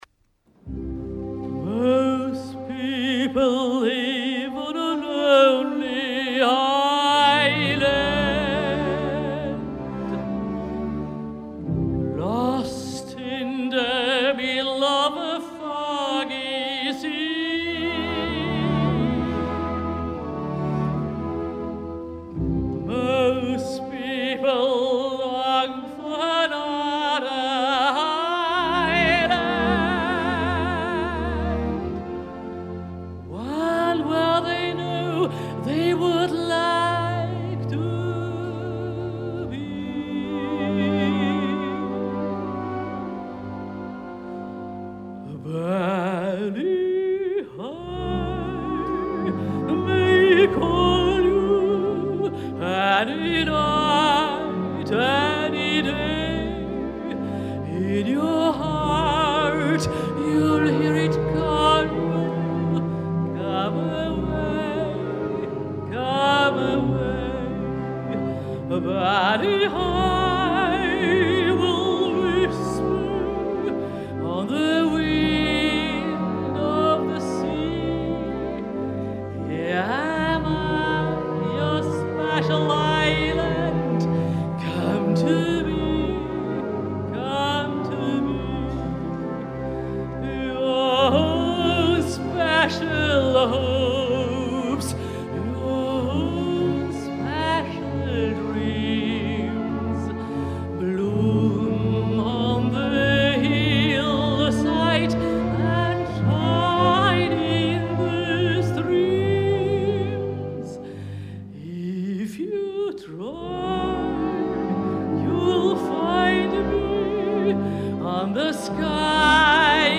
concert semi escenificat